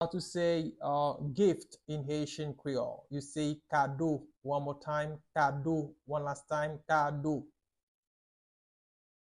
Pronunciation:
Listen to and watch “Kado” audio pronunciation in Haitian Creole by a native Haitian  in the video below:
14.How-to-say-Gift-in-Haitian-Creole-–-Kado-with-pronunciation.mp3